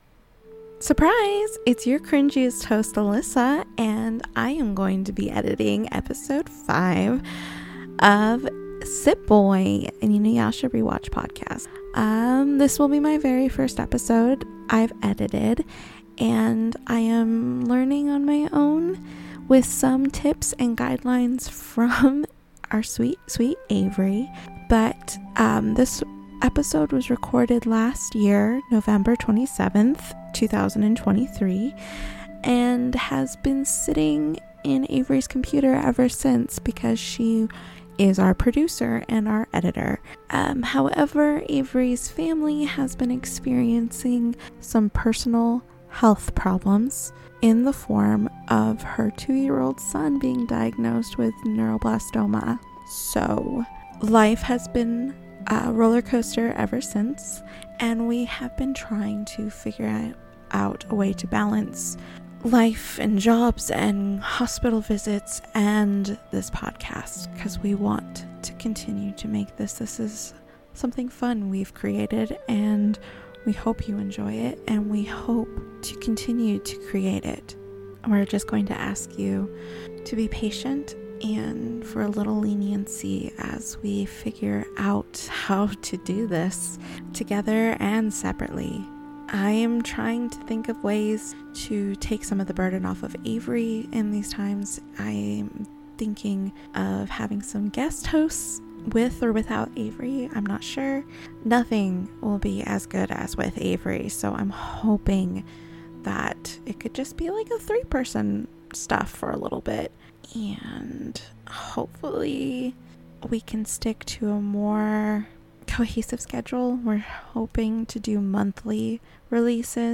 Join us in our questions about Lunar cycles and try to hear us squee over the dialogue choices.
Thanks for bearing with us with some audio inconsistencies - next episode will be in our new studio set up and we each have our own mic now!!